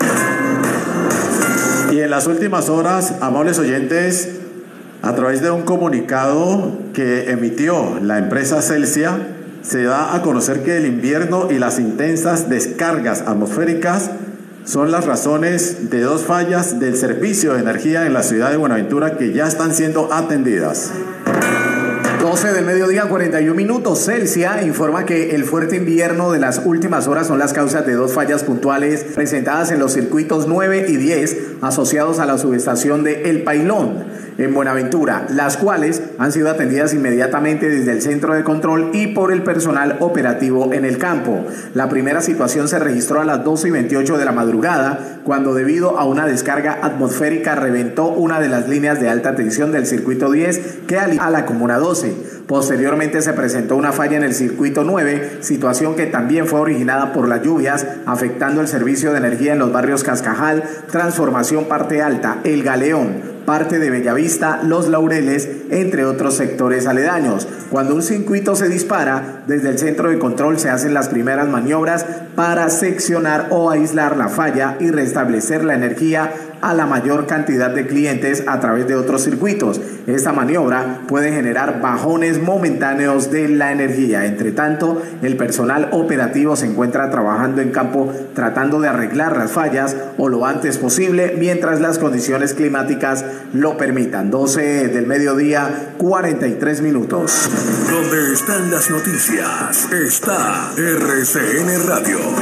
Radio
comunicado de prensa